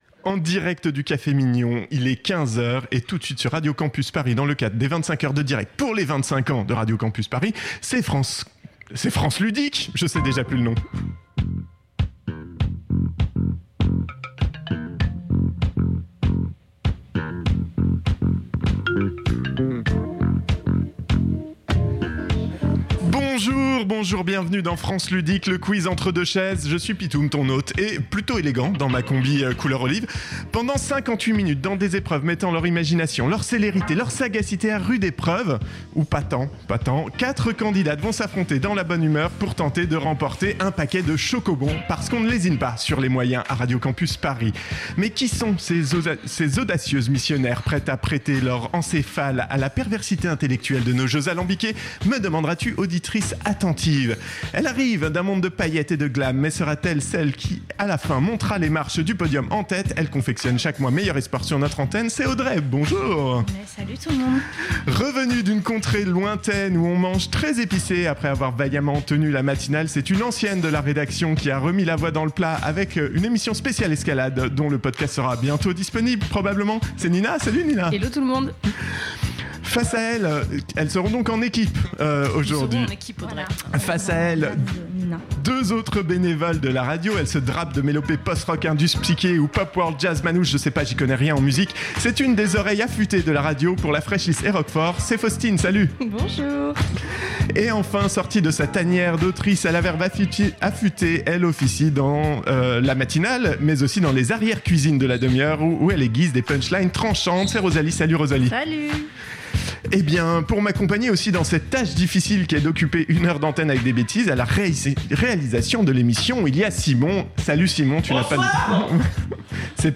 25h de direct en 24h - Radio Campus Paris
Pendant 58 minutes quatre candidates vont s’affronter dans la bonne humeur dans des épreuves mettant leur imagination, leur célérité et leur sagacité à rude épreuve (ou pas tant que ça) pour tenter de remporter un paquet de schokobons parce qu’on ne lésine pas sur les moyens à Radio Campus Paris.